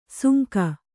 ♪ sunka